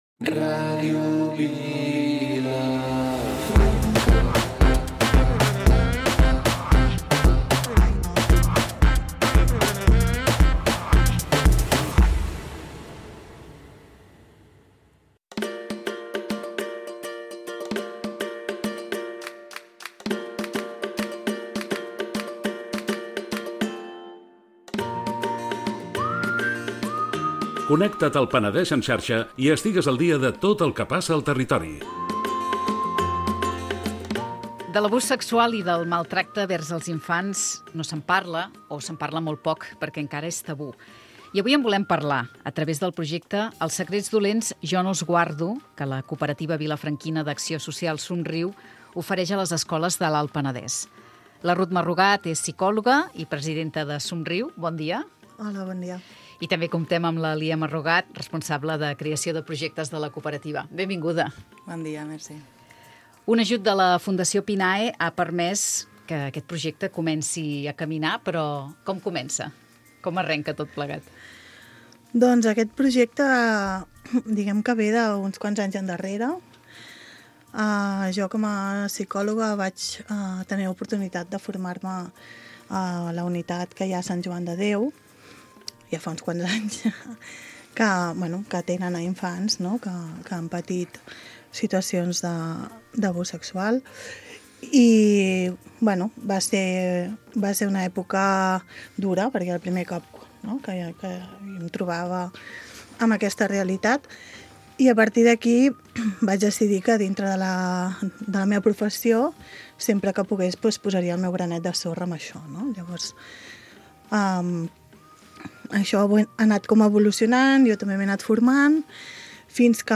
Magazín matinal